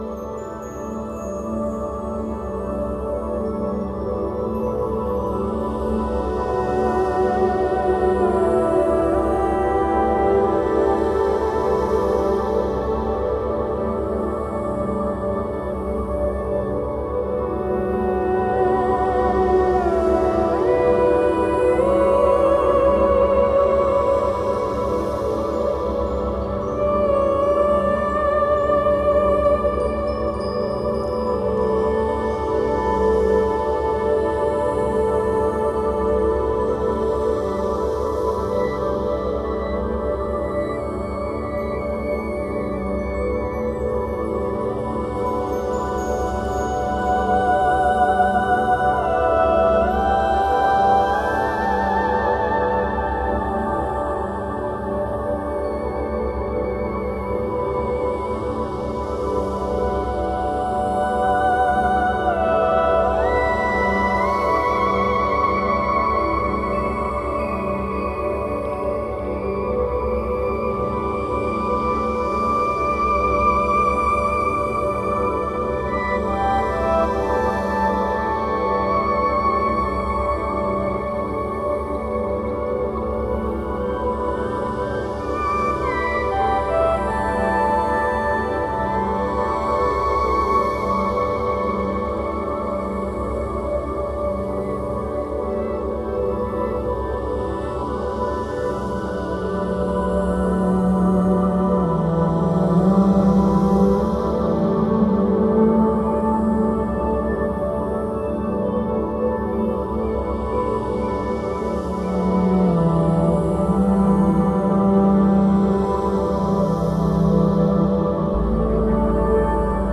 Beautiful ambient space music.
Cello
Tagged as: Ambient, New Age, Space Music